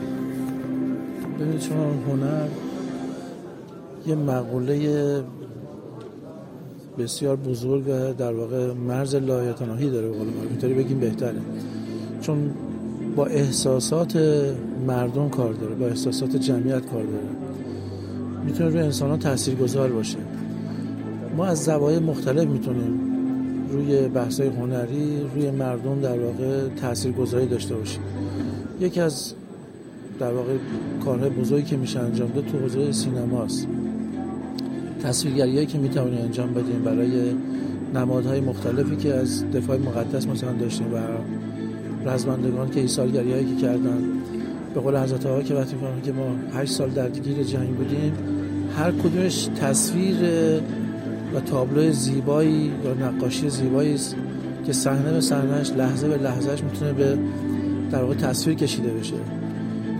به همین مناسبت ایکنا با امیر سرتیپ مهدی خواجه‌امیری؛ معاون هماهنگ‌کننده وزارت دفاع پیرامون جایگاه سینما در پرداختن به موضوع دفاع مقدس گفت‌وگویی انجام داده که در ادامه با آن همراه می‌شویم.